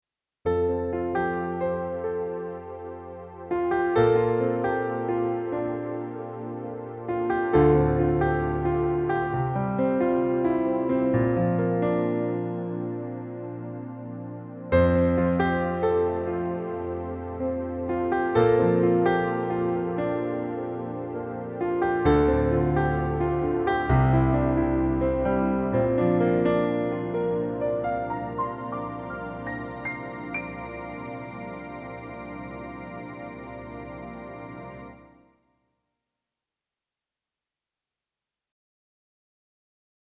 V3 Sound Grand Piano XXL Test ► Der Sound-Expander mit hochwertigen Samples für Piano, Orchester und Synth Sounds ► Jetzt lesen!
Die Soundbeispiele zeigen einen Querschnitt der Sounds und wurden direkt beim Durchsteppen der Soundbänke von mir eingespielt.